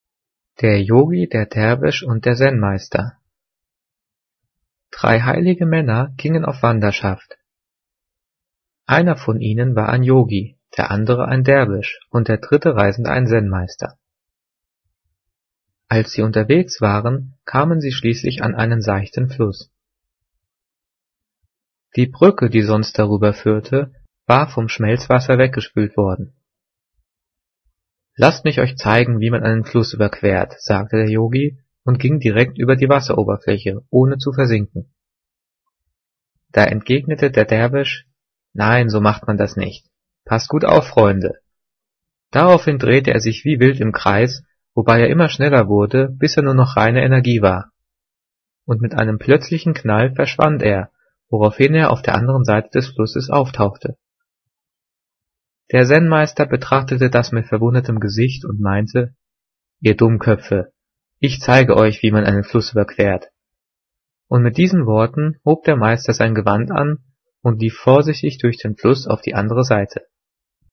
Gelesen:
gelesen-der-yogi-der-derwisch-und-der-zen-meister.mp3